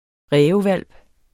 Udtale [ ˈʁεːvə- ]